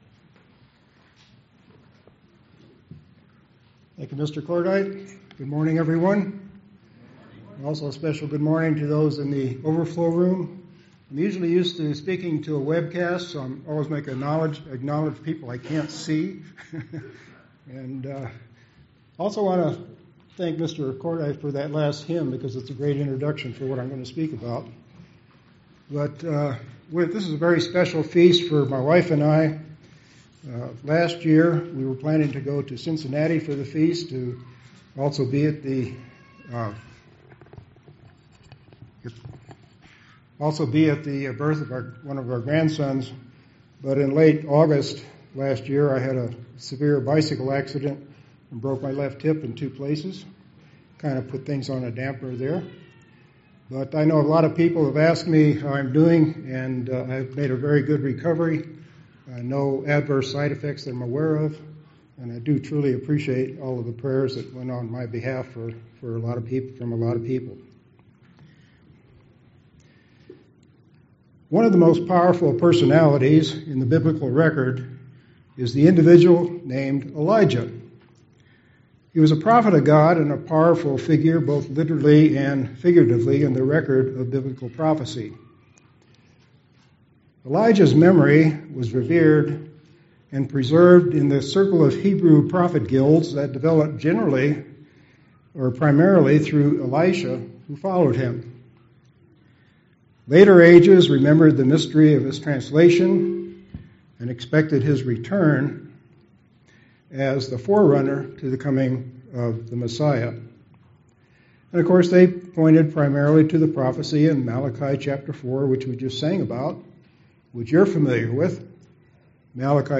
This sermon was given at the Steamboat Springs, Colorado 2018 Feast site.